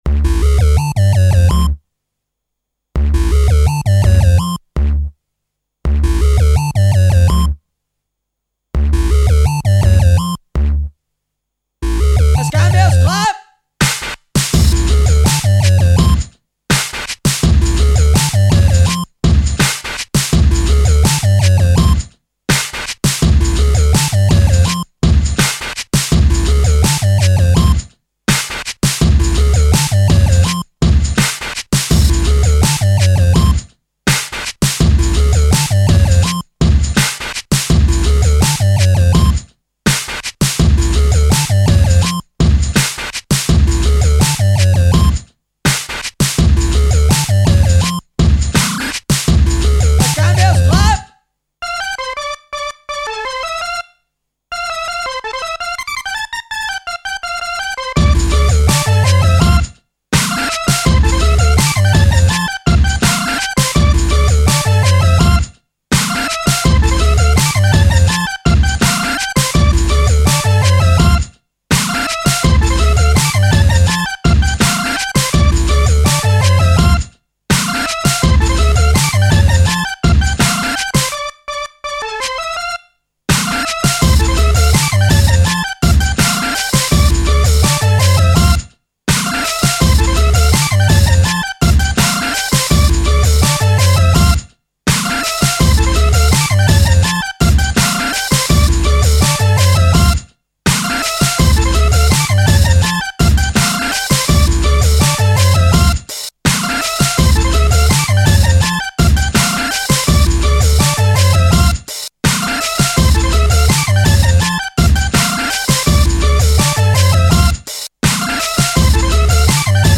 stereo